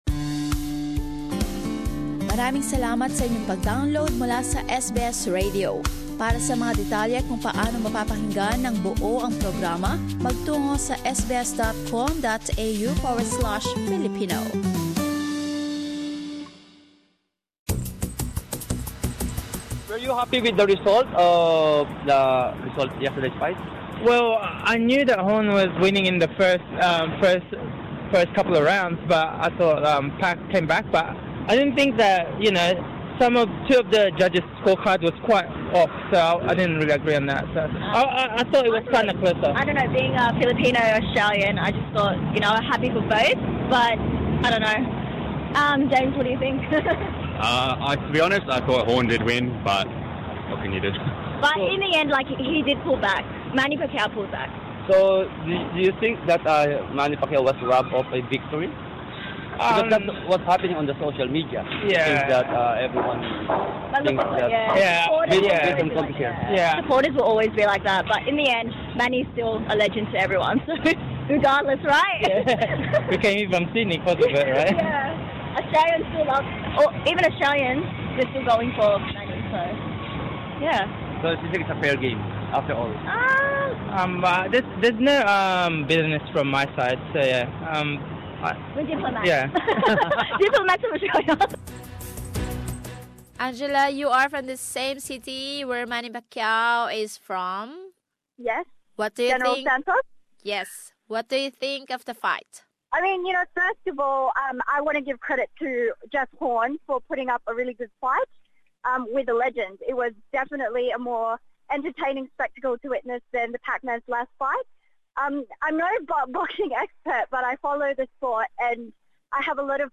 Here are some reactions of boxing supporters in interviews